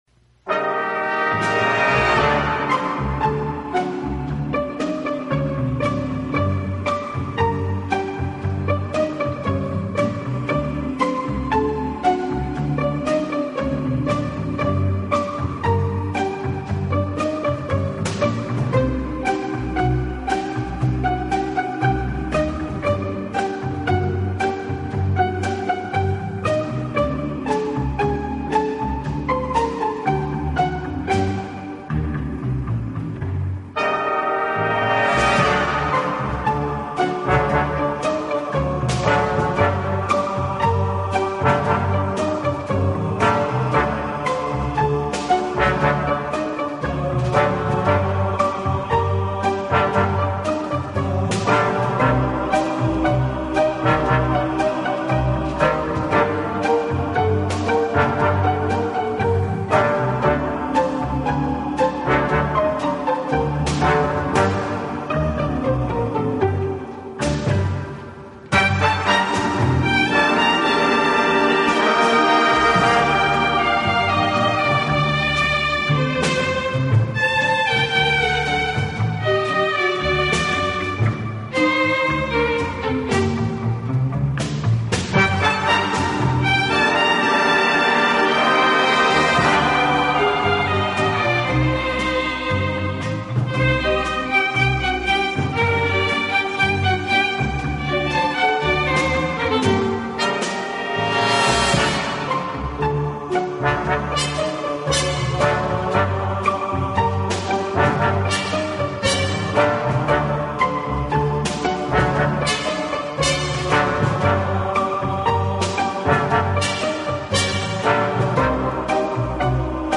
【轻音乐】
温情、柔软、浪漫是他的特色，也是他与德国众艺术家不同的地方。